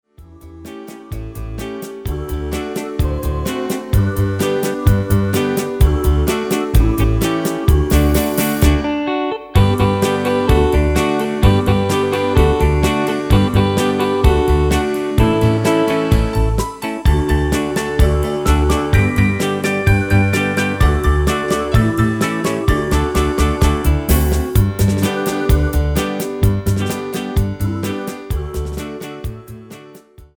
Demo/Koop midifile
Genre: Evergreens & oldies
Toonsoort: D
- Géén vocal harmony tracks
Demo's zijn eigen opnames van onze digitale arrangementen.